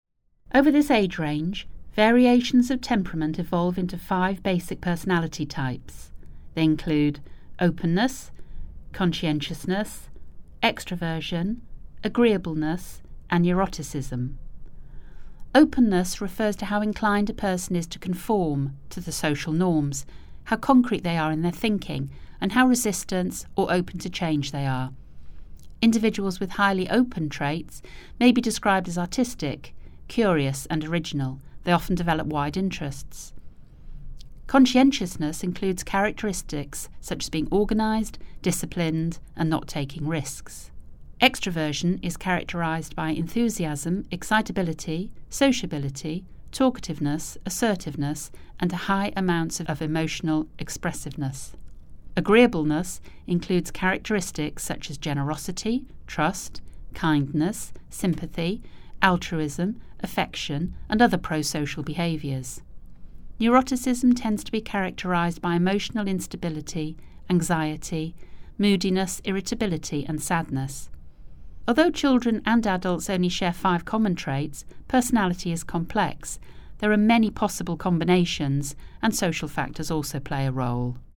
Narration audio (MP3)